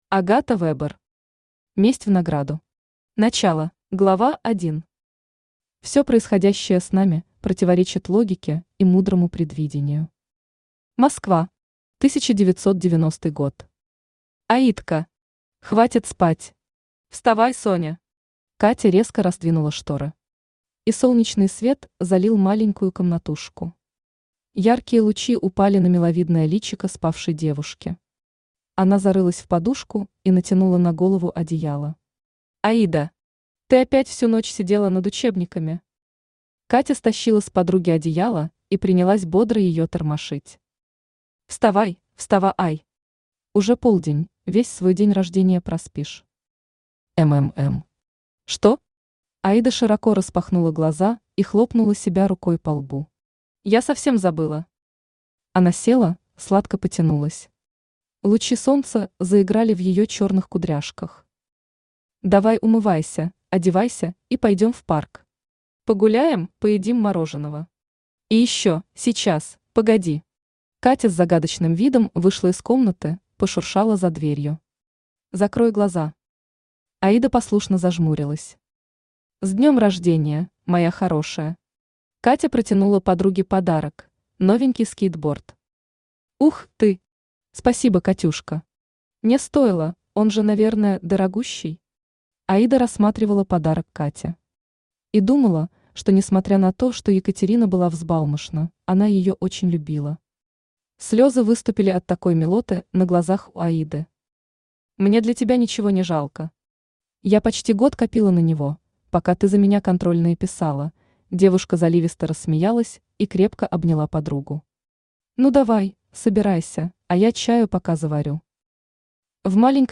Аудиокнига Месть в награду. Начало | Библиотека аудиокниг
Начало Автор Агата Вебер Читает аудиокнигу Авточтец ЛитРес.